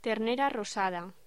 Locución: Ternera rosada